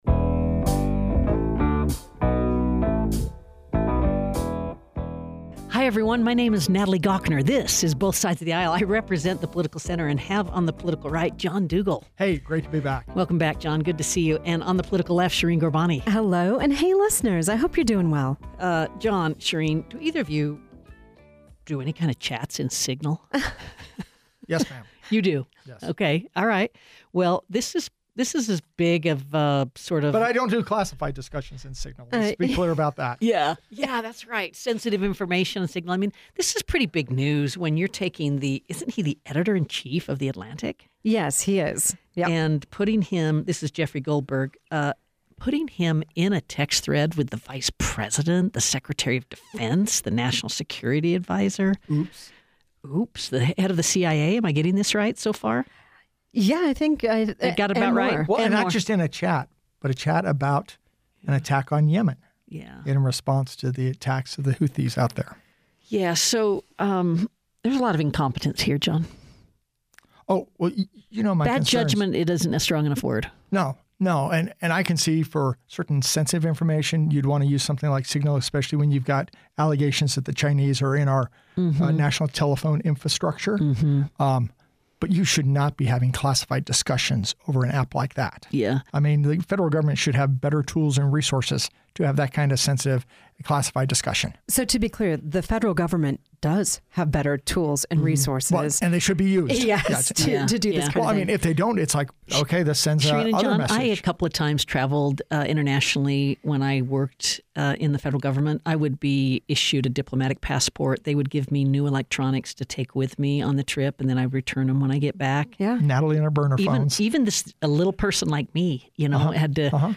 Shireen Ghorbani and Salt Lake County District Attorney Sim Gill discuss crime levels, crime prevention, and the politics surrounding them.